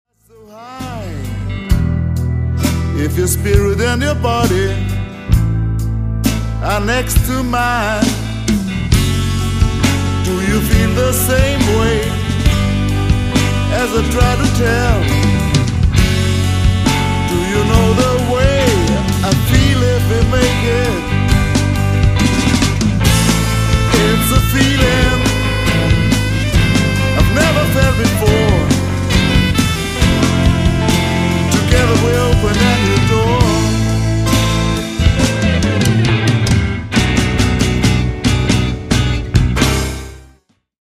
Recorded at Electric Sounddesign Studio, Linz/Austria 2003.
guitars, lead vocals
bass, vocals
drums